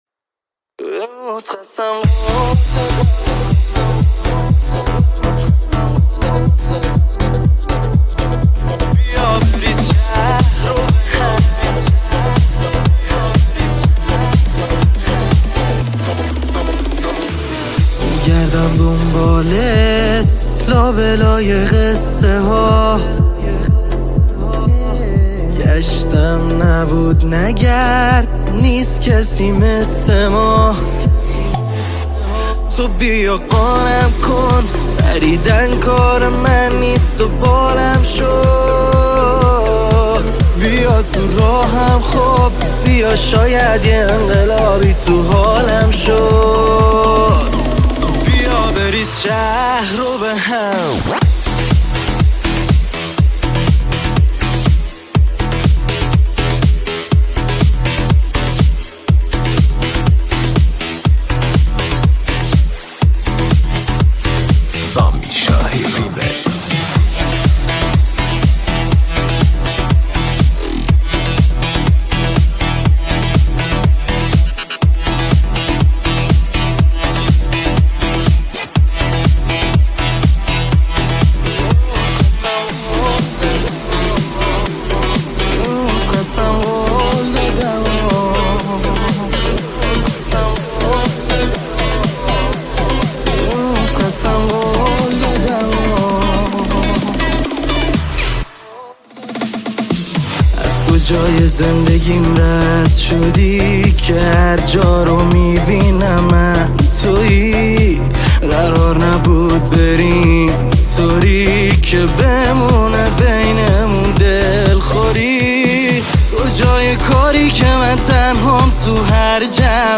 ریمیکس جدید آهنگ